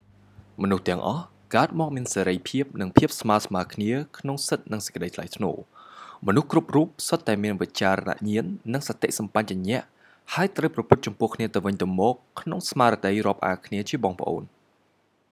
크메르어로 쓴 세계인권선언 제1조 낭독